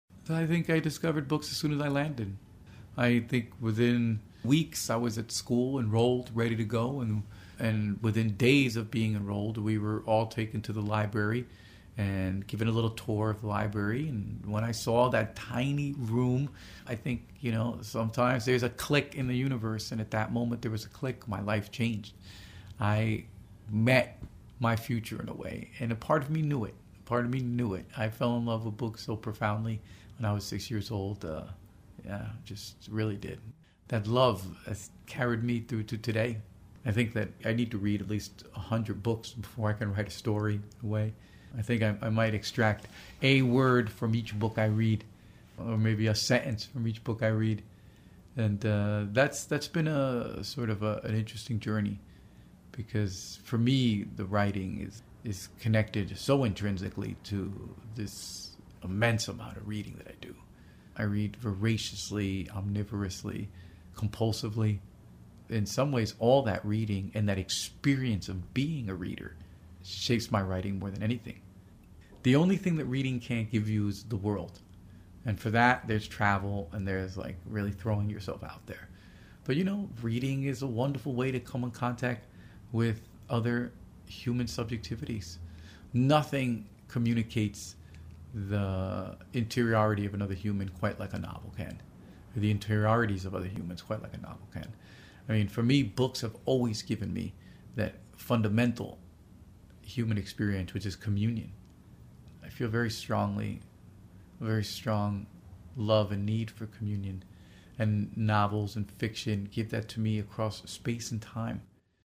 stories-junot-diaz-reading-edit.mp3